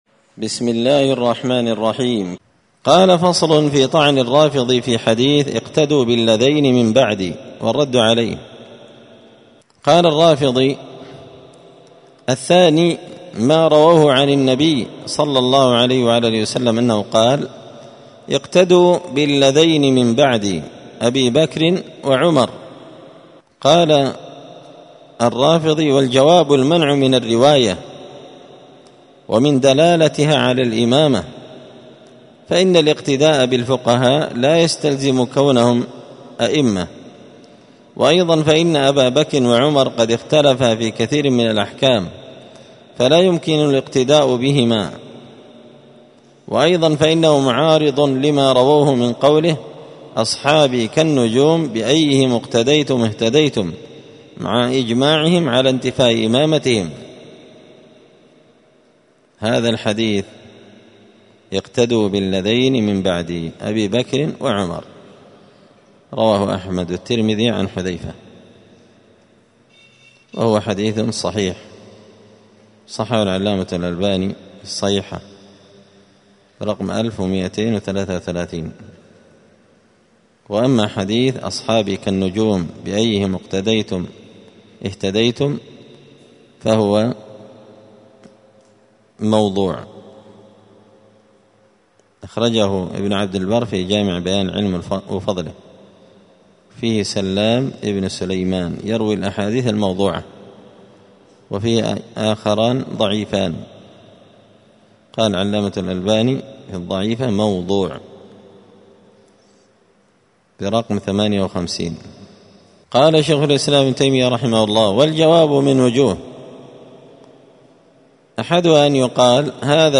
*الدرس الحادي والأربعون بعد المائتين (241) فصل في طعن الرافضي في حديث (اقتدوا بالذين من بعدي) والرد عليه*